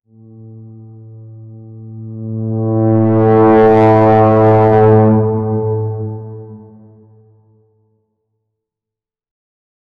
Low, distant steamship horn echoing over the Bosphorus, atmospheric and muffled, no modern tones, no music.
low-distant-steamship-hor-wle6k5kp.wav